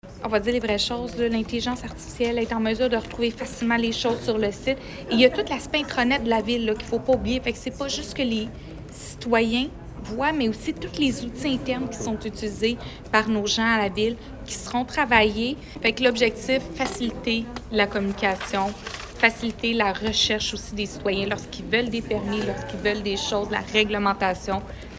La mairesse de Granby, Julie Bourdon : Granby, nouveau site web + panneaux, 21.01.25_Bourdon, clip L’objectif souhaité par l’administration municipale est d’avoir le nouveau site web accessible cet été.